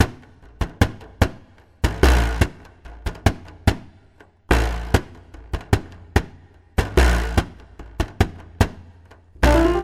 • 98 Bpm 2000s Drum Groove G Key.wav
Free breakbeat sample - kick tuned to the G note. Loudest frequency: 788Hz